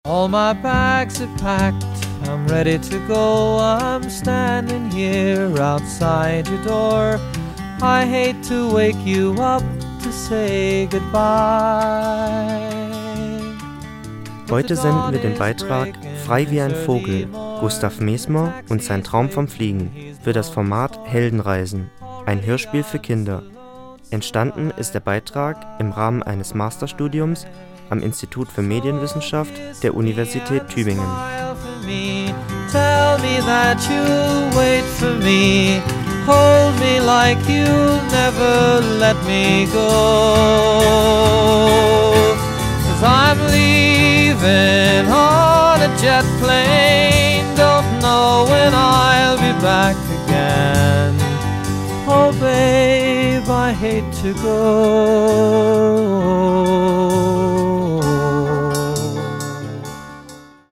Ein Hörspiel für Kinder.
Die Sprecherinnen und Sprecher in ihren Rollen: